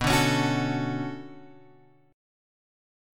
B Minor 9th